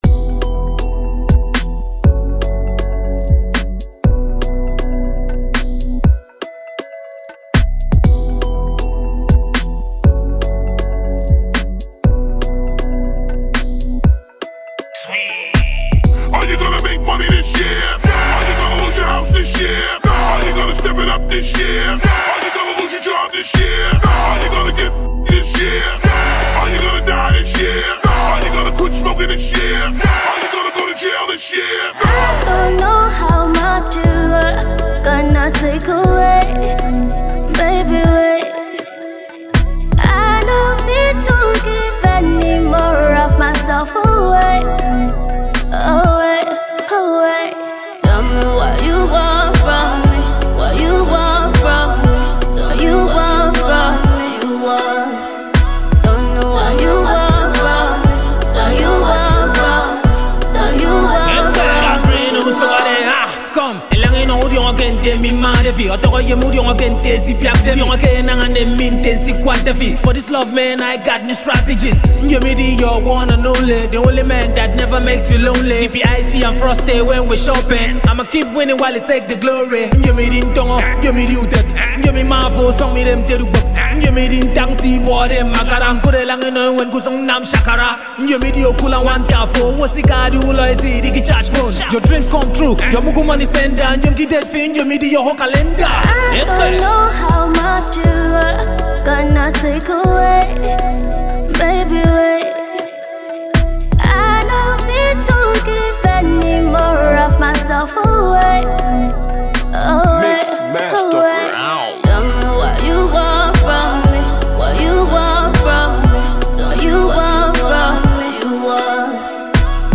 silky smooth voiced